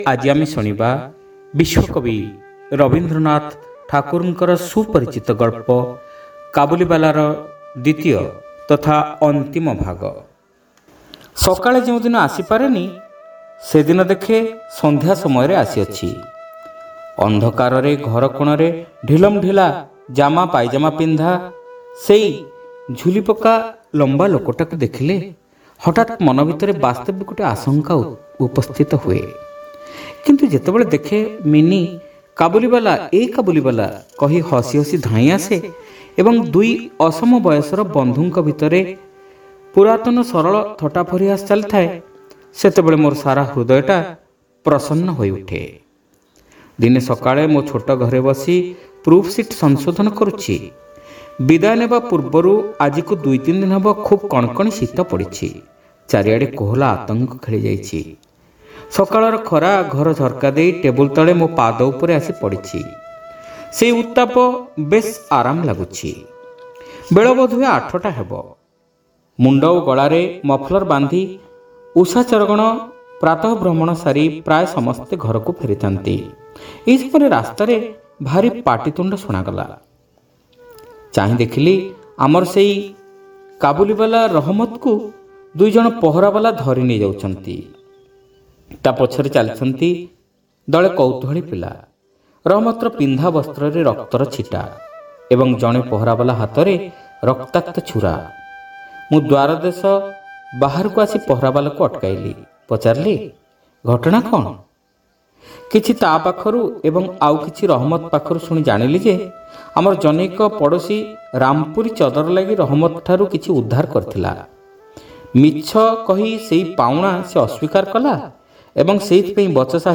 Audio Story : Kabulibala (Part-2)